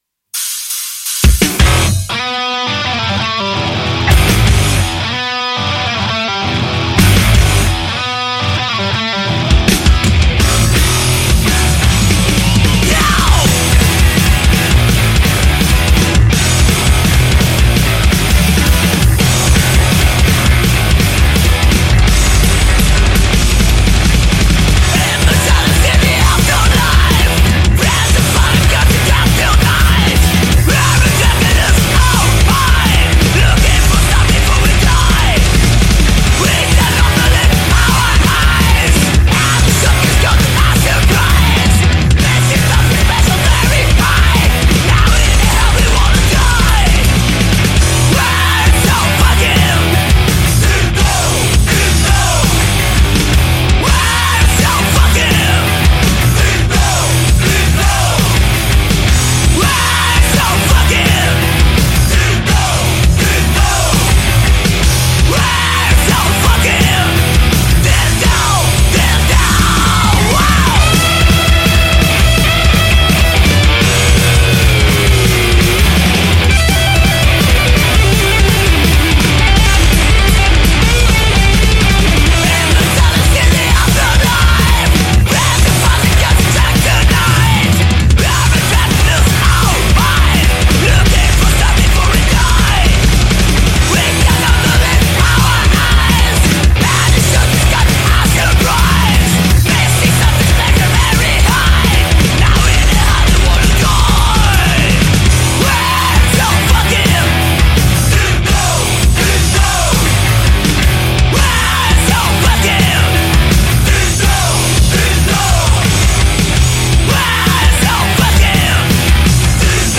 Intervista agli Underball | 13-6-22 | Radio Città Aperta